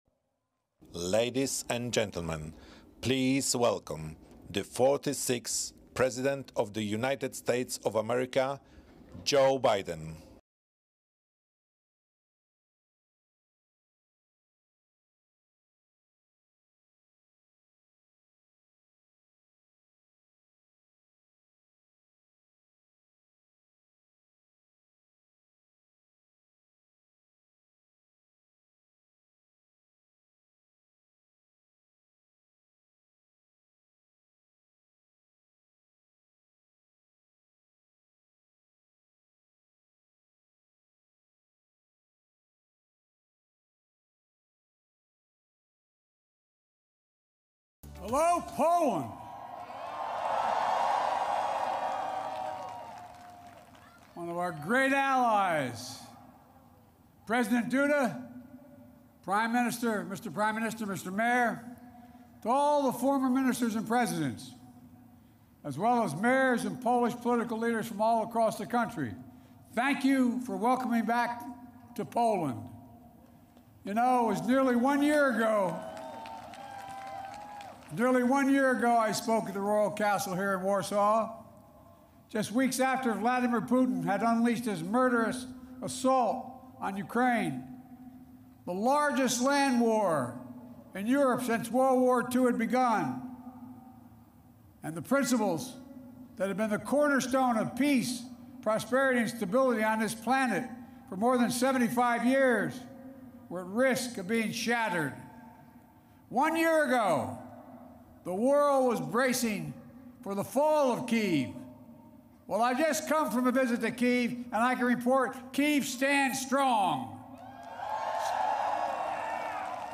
Presidential Speeches
biden_remarks_ukraine_oneyear_anniversary.mp3